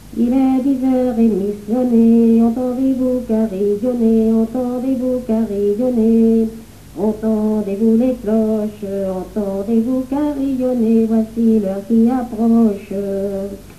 Catégorie Pièce musicale inédite